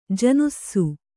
♪ janussu